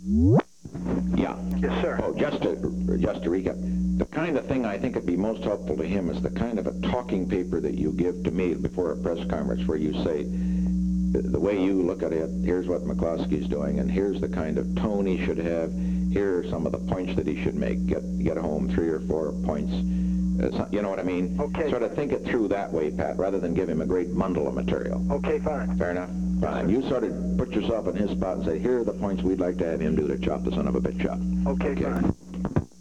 Secret White House Tapes
Location: White House Telephone
The President talked with Patrick J. Buchanan.